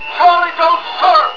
Sound Bites: